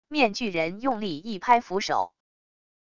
面具人用力一拍扶手wav音频